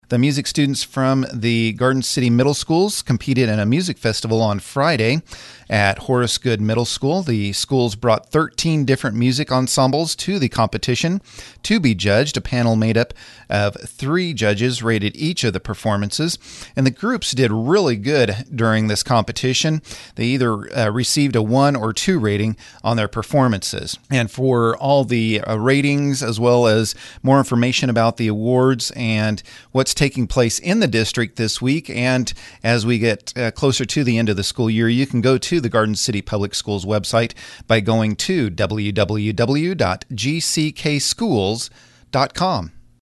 gives the weekly update on the school district and gives valuable information to parents, students, and those in the community